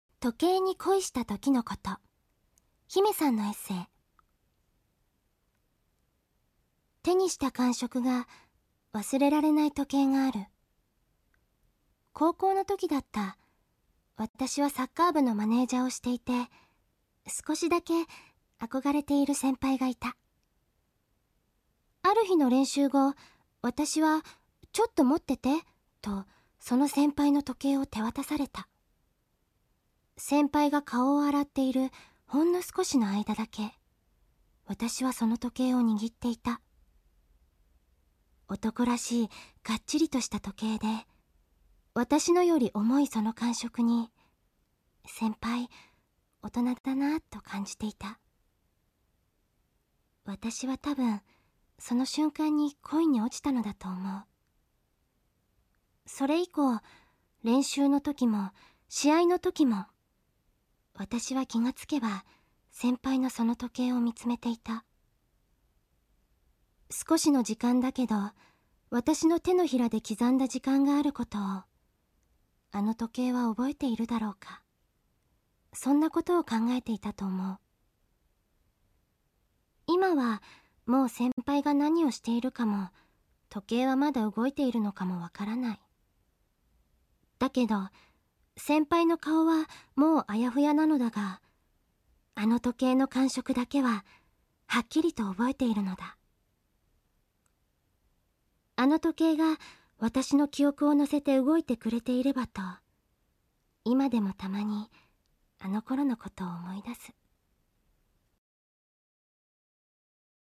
さらに、この本は、朗読（ろうどく）ボイスCD（シーディー）がついていて、朗読してくれているのは、このサイトではチョッチイの声。そして人気（にんき）アニメでは「キャプテン翼（つばさ）」の中沢早苗（なかざわさなえ）（やく）、「ヒカルの碁（ご）」では奈瀬明日美（なせあすみ）役をやっている大人気（だいにんき）声優（せいゆう）の榎本温子（えのもとあつこ）さんなのである。
特別（とくべつ）にその朗読の中から、「時計に恋したときのこと（とけいにこいしたときのこと）」という作品を聞いて（きいて）いただこうと思うのである。